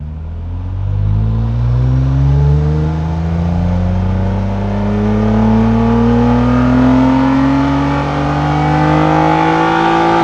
rr3-assets/files/.depot/audio/Vehicles/ttv6_02/ttv6_02_accel.wav
ttv6_02_accel.wav